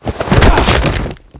tackle1.wav